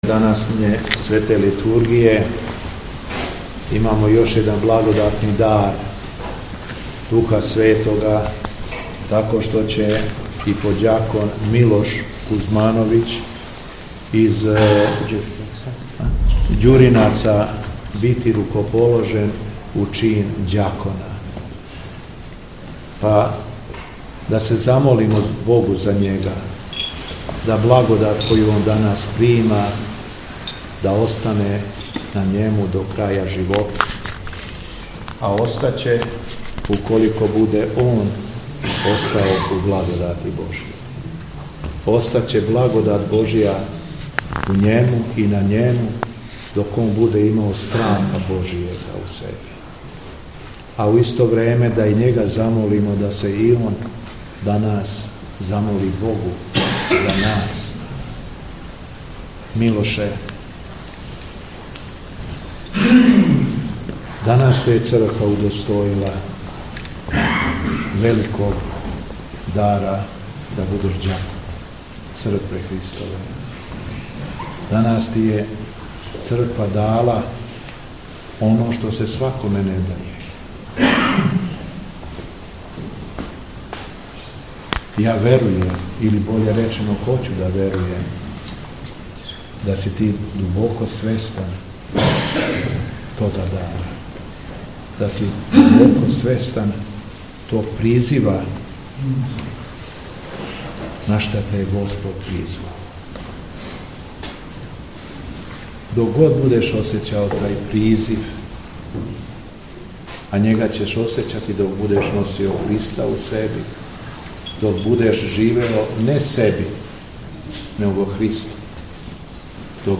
Беседа Његовог Високопреосвештенства Митрополита шумадијског г. Јована